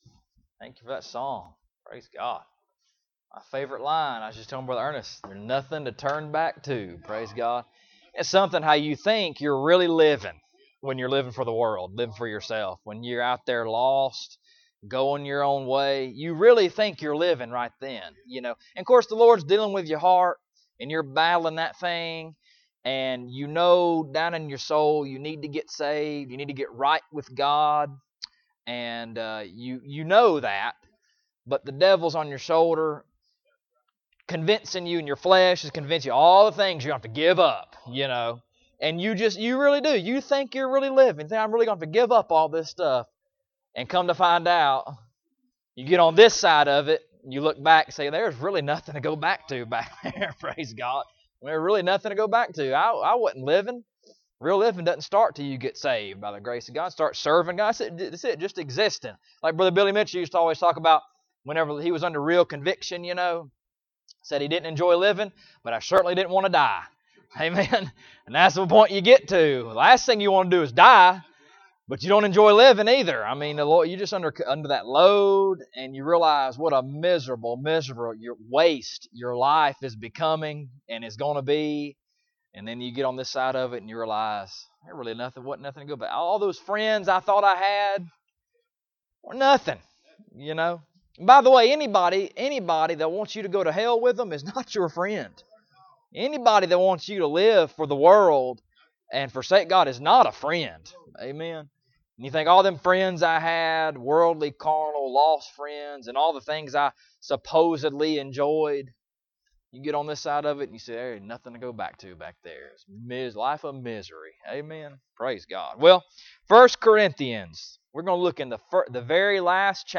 1 Corinthians 16:13 Service Type: Sunday Morning Bible Text